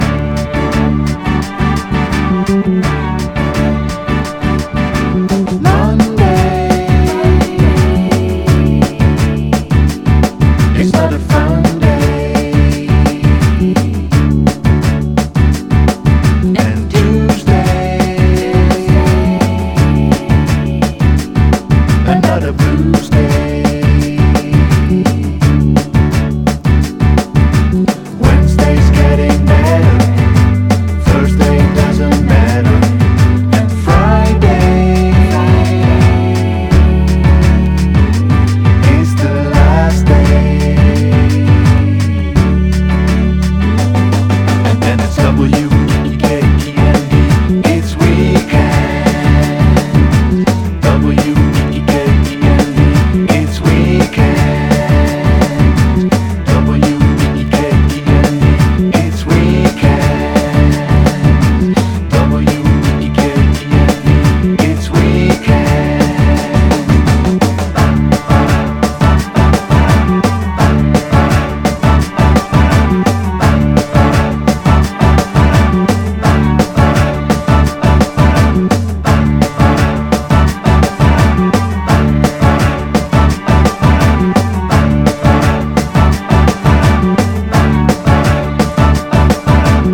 JAPANESE CLUB / BREAKBEATS
ド・ファンキー・カットアップ・ブレイクス